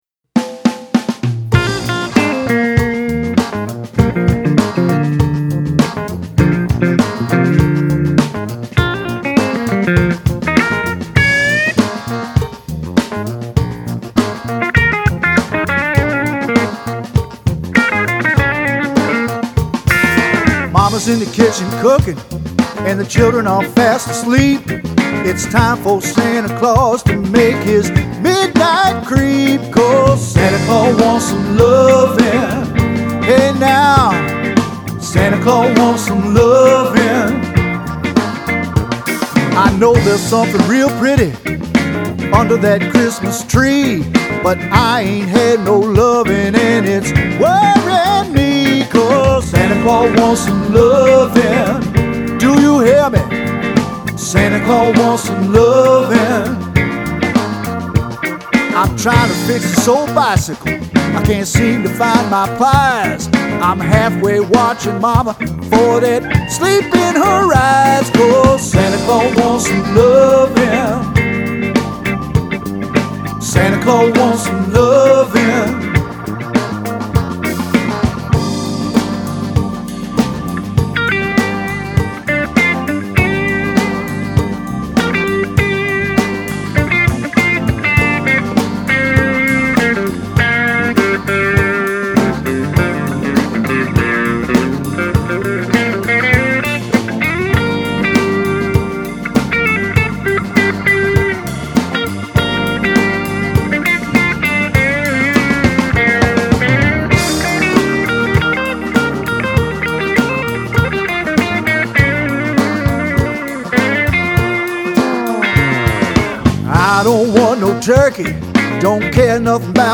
Up beat and swinging
vocals and saxophone
electric and upright bass
drums and percussion